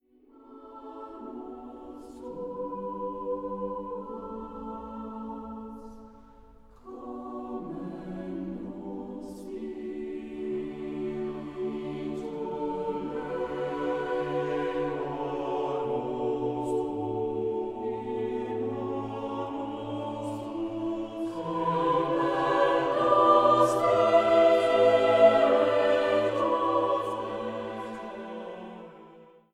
für vier- bis achtstimmig gemischten Chor a cappella